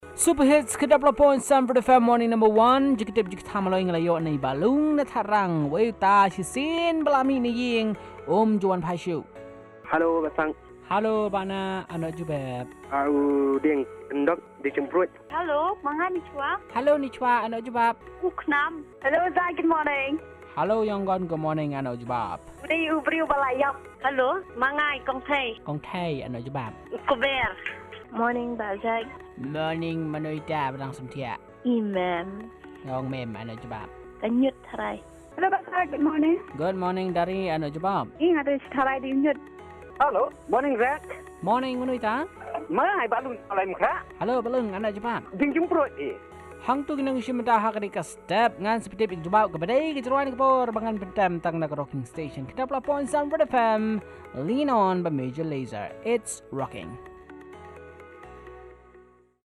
Callers with their answers